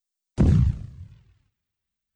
Big Foot Stomp Sound.wav